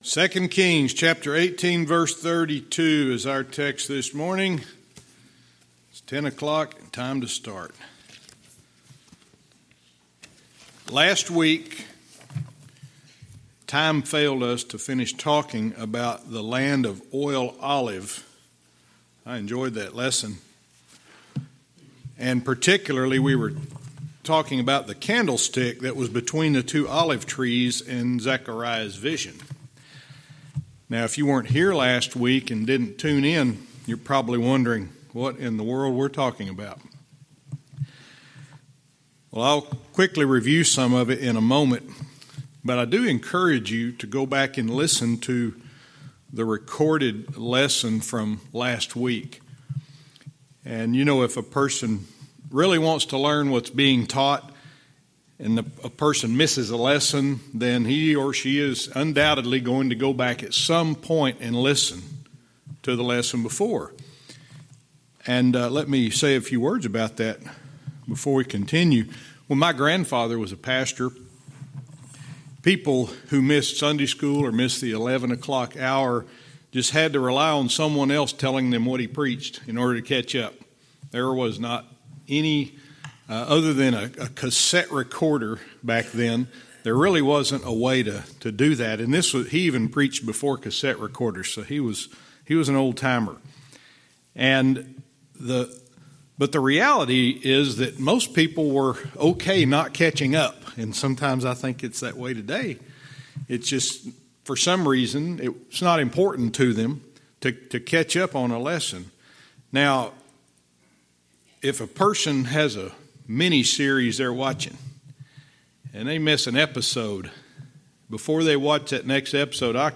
Verse by verse teaching - 2 Kings 18:32 Part 4